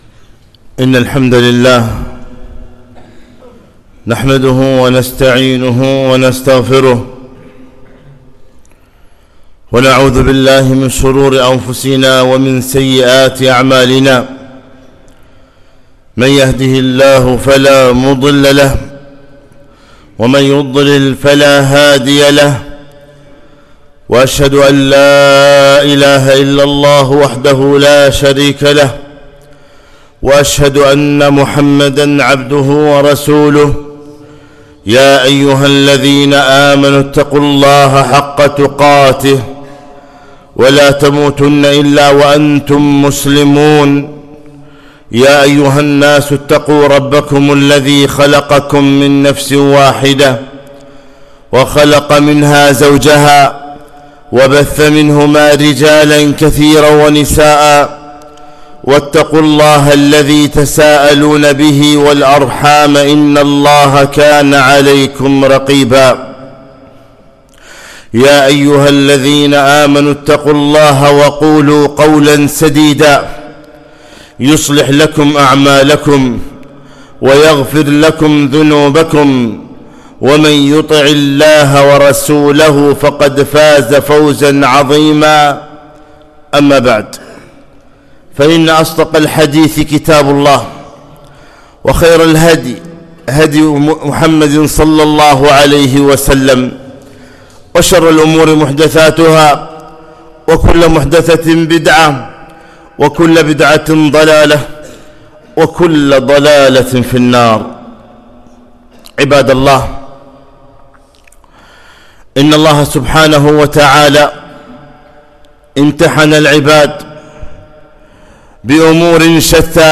خطبة - اتقوا فتنة النساء